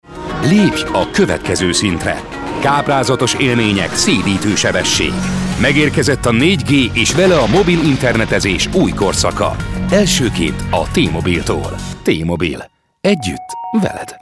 Hungarian, ungarische voice talent, sprecher, deep, east-european, warm
Sprechprobe: Industrie (Muttersprache):
Hungarian voice actor, voice talent, deep, warm